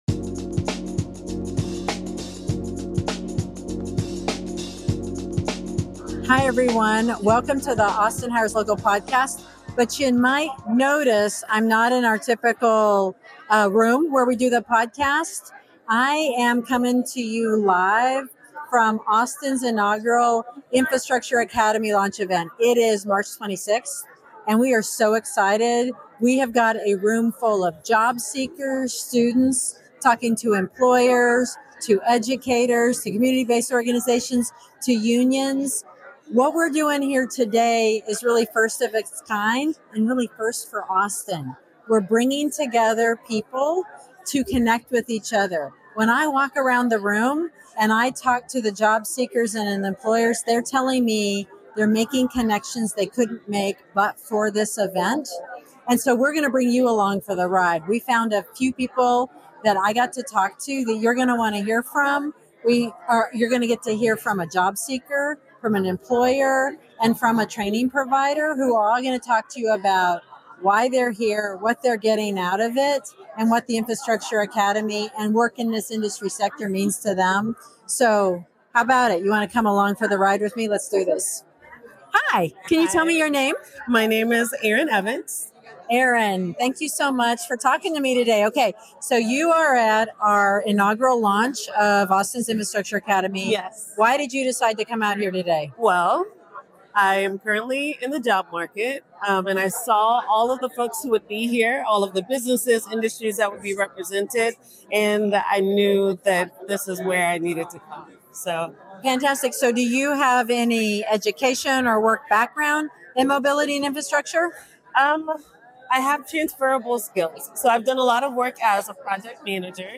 explores the untapped potential of this dynamic sector at the inaugural launch of Austin's Infrastructure Academy.